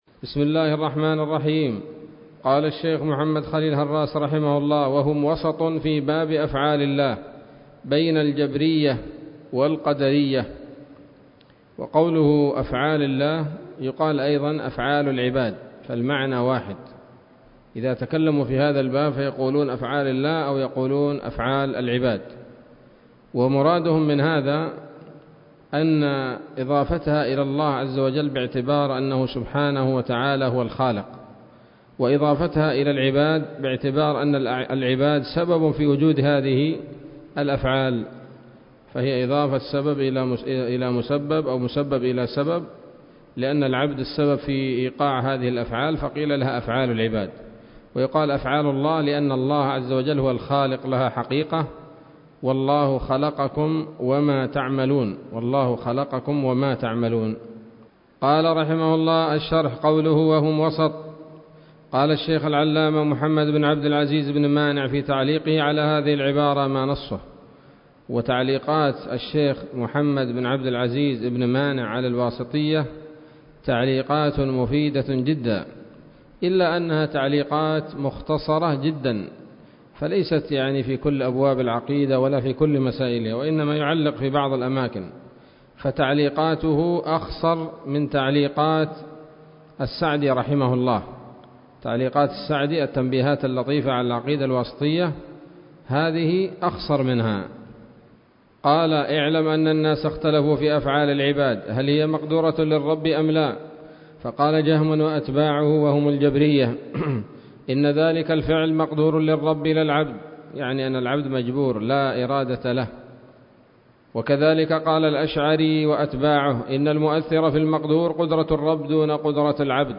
الدرس التسعون من شرح العقيدة الواسطية للهراس